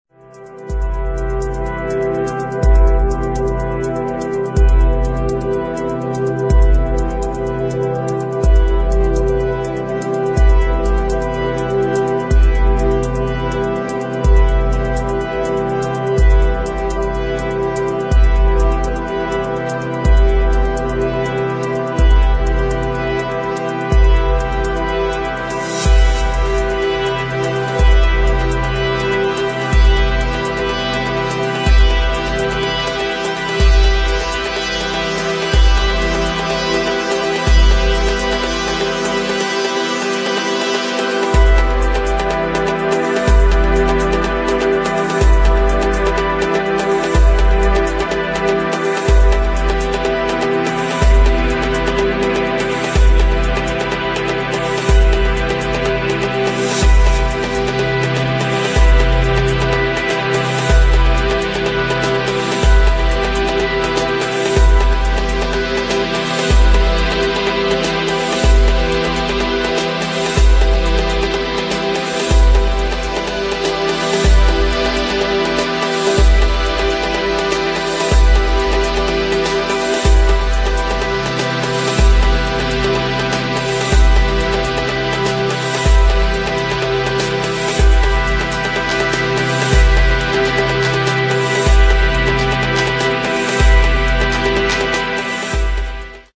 [ TECHNO / BASS ]